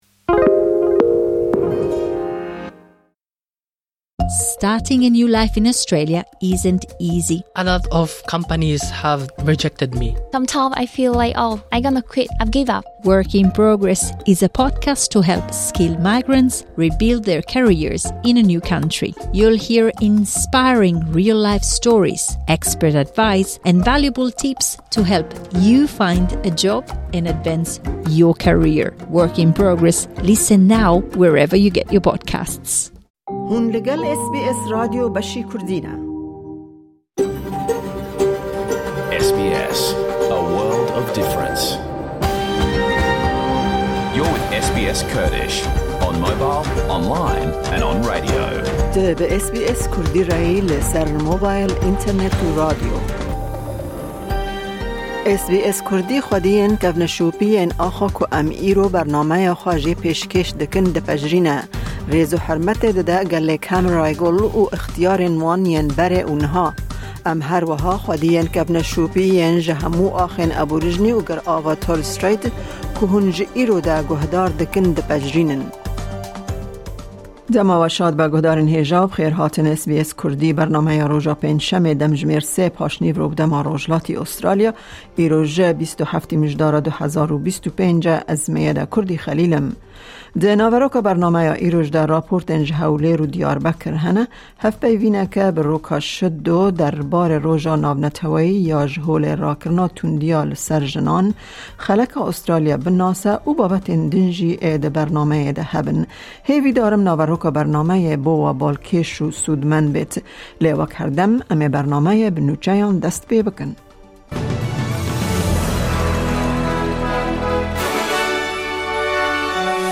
Nûçe, raportên ji Hewlêr û Amedê, herweha hevpeyvîna